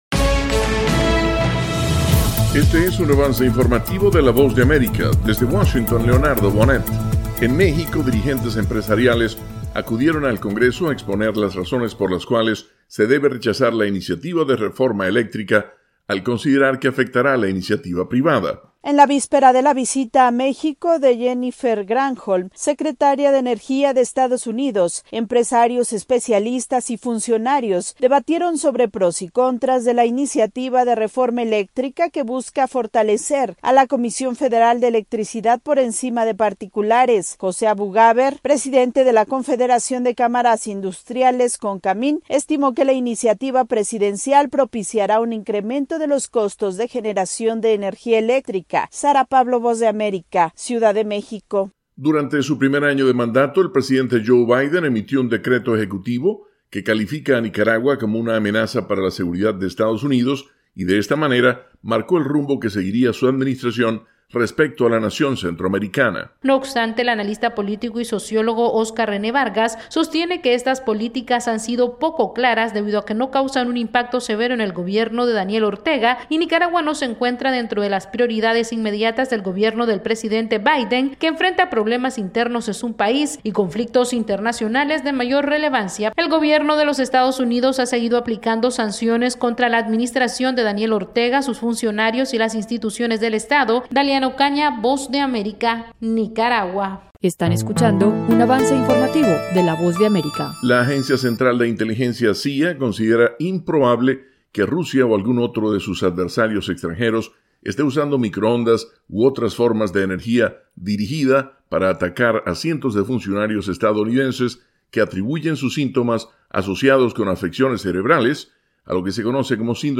Resumen informativo con algunas de las noticias más importantes de Estados Unidos y el resto del mundo.